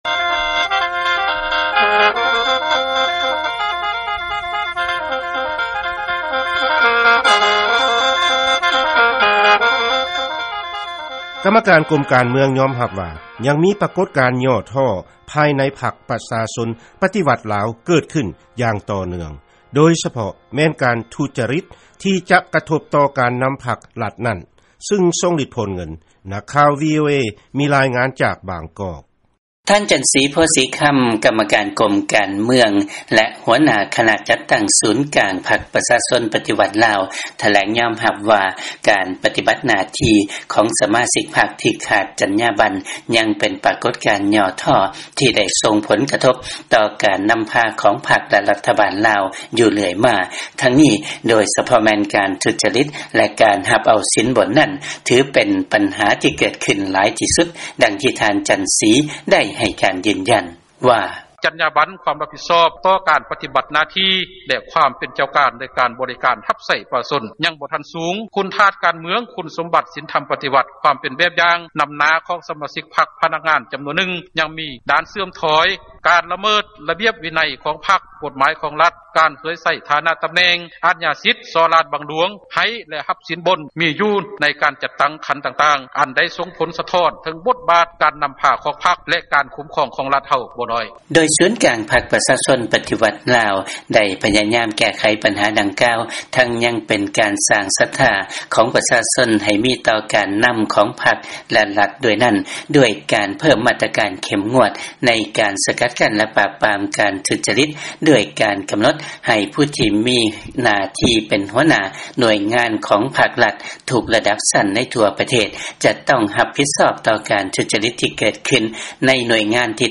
ຟັງລາຍງານ ກຳມະການກົມການເມືອງ ຍອມຮັບວ່າມີ ປະກົດການຫຍໍ້ທໍ້ພາຍໃນພັກປະຊາຊົນ ປະຕິວັດ ລາວ ເພີ່ມຂຶ້ນ